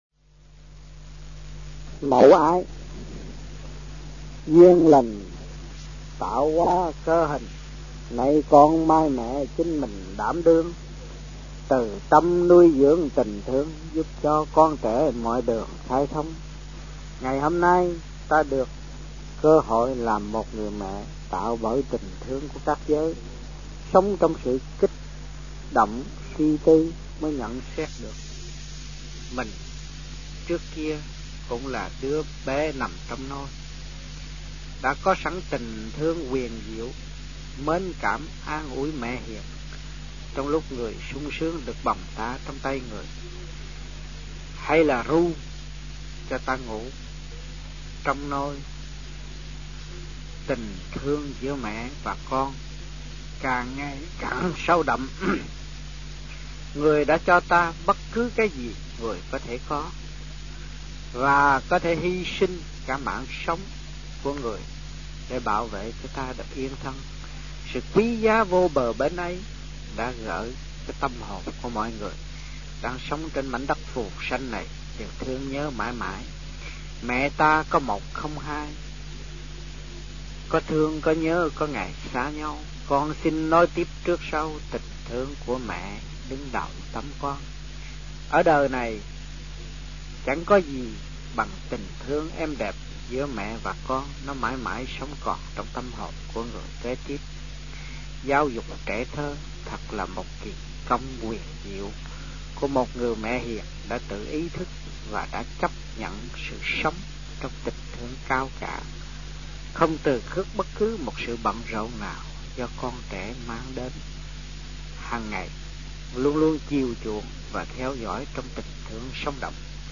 Địa danh : Sài Gòn, Việt Nam
Trong dịp : Sinh hoạt thiền đường